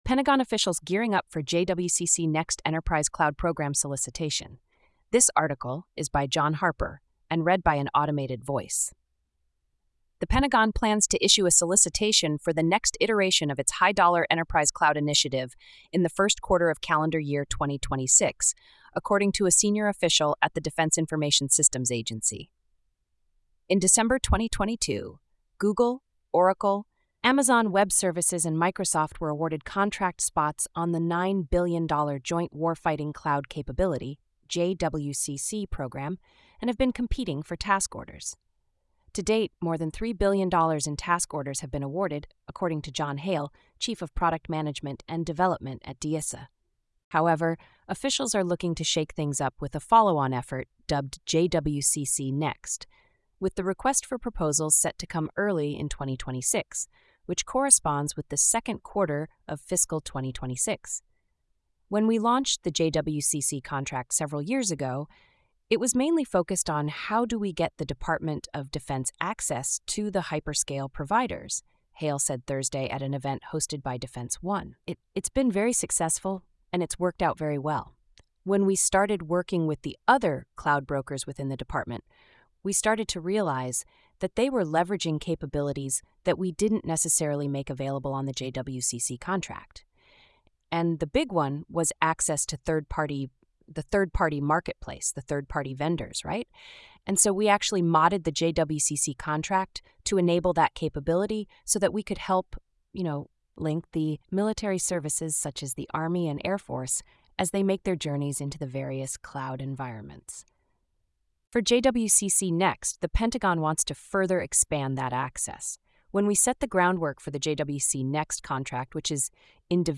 This feature uses an automated voice, which may result in occasional errors in pronunciation, tone, or sentiment.
english.openai.mp3